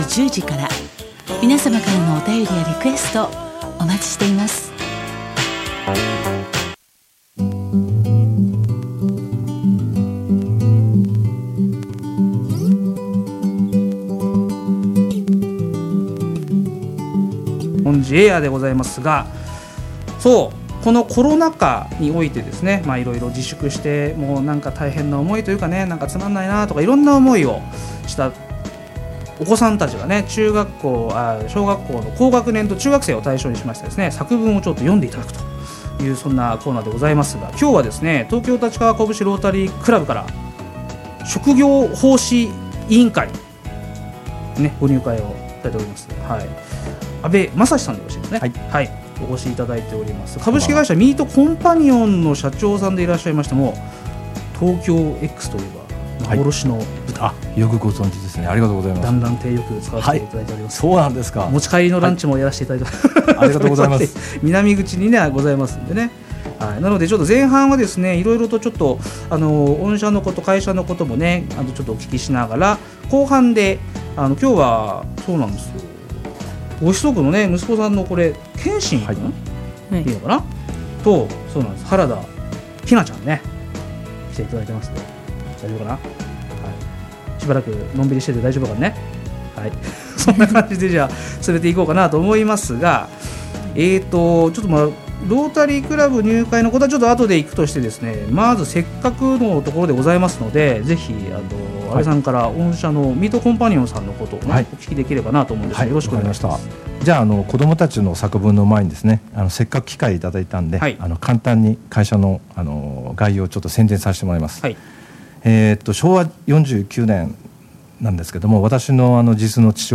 ローカルラジオ
REC-FMたちかわ.mp3